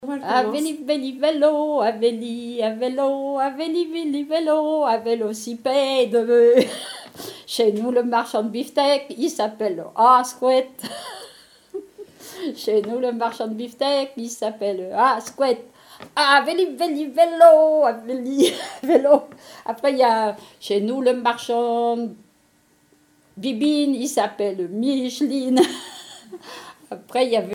Témoignages sur les chansons
Pièce musicale inédite